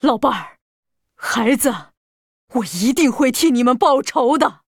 文件 文件历史 文件用途 全域文件用途 Balena_amb_01.ogg （Ogg Vorbis声音文件，长度4.7秒，94 kbps，文件大小：54 KB） 源地址:游戏语音 文件历史 点击某个日期/时间查看对应时刻的文件。